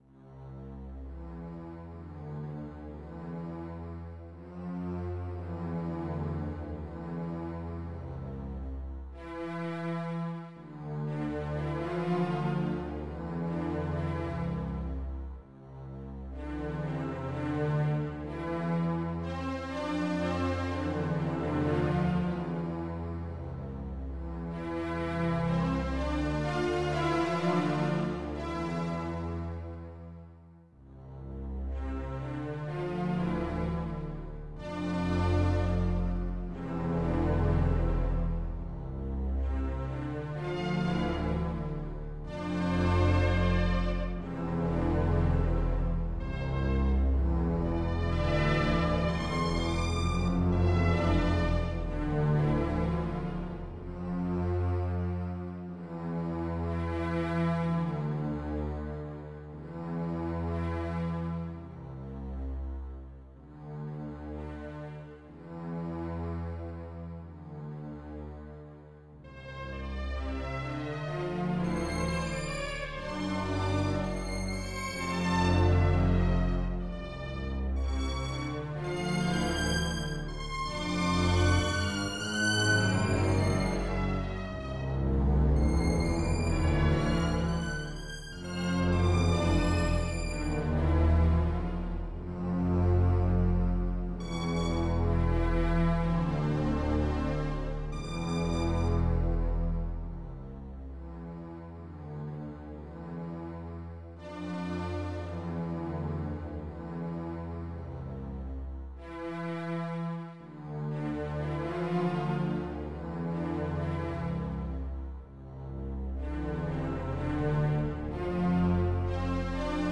A classical music collection